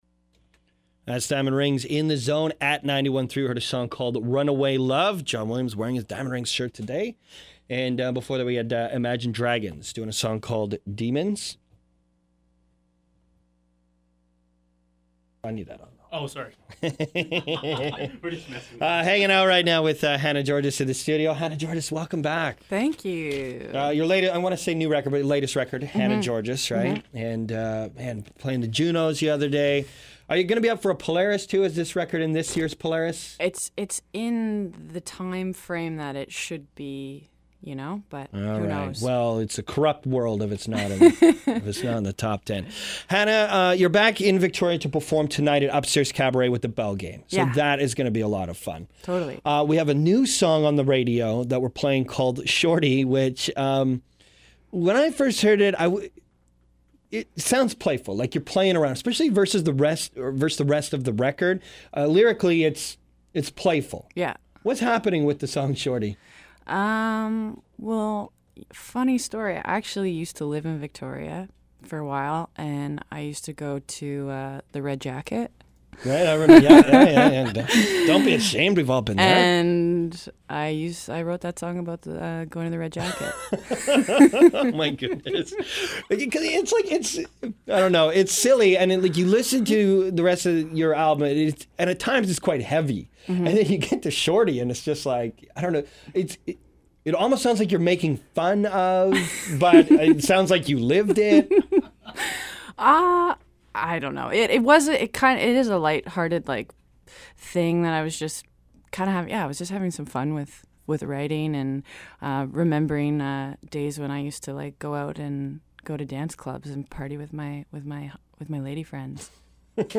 We also had a chance to have an interview…. here it is.